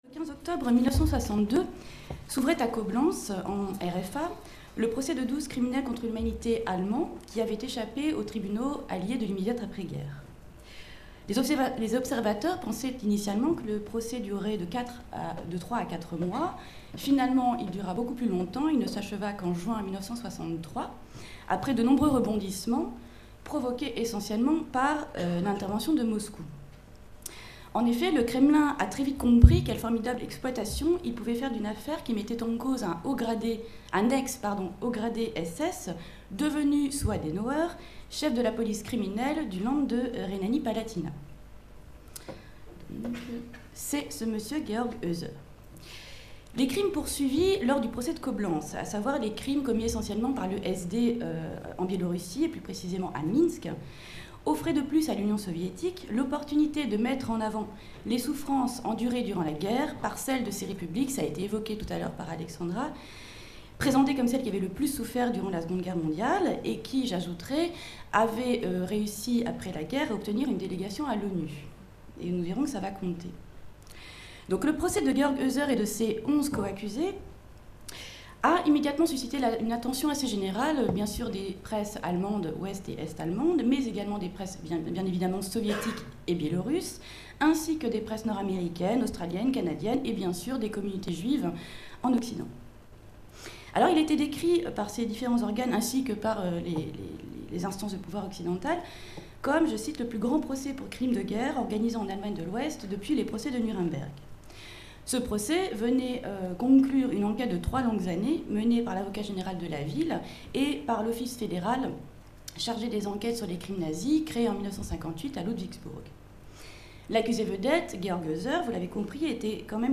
Cette conférence a été donnée dans le cadre du colloque Mémoires des massacres au XXe siècle organisé par le Centre de recherche en histoire quantitative (CRHQ) de l'Université de Caen et le Mémorial de Caen du 22 au 24 novembre 2017.